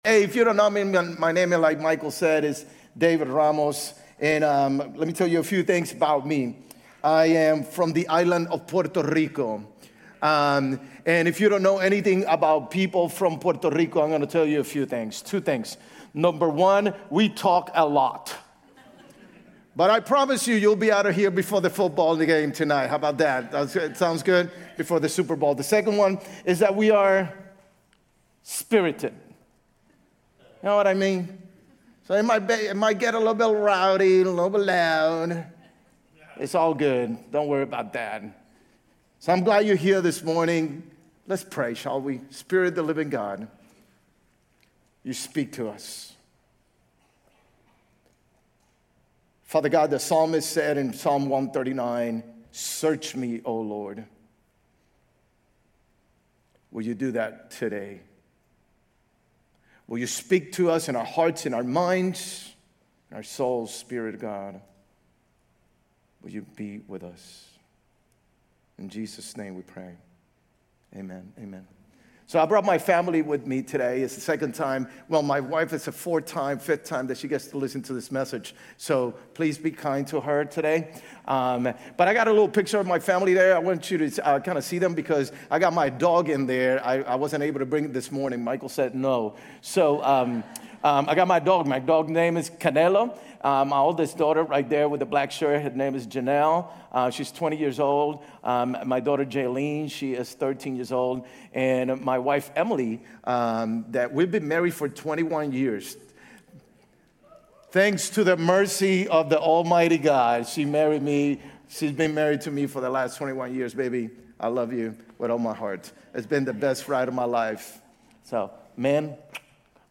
Grace Community Church University Blvd Campus Sermons 2_8 University Blvd Campus Feb 09 2026 | 00:40:28 1x 00:00 / 00:40:28 Subscribe Share RSS Feed Share Link Embed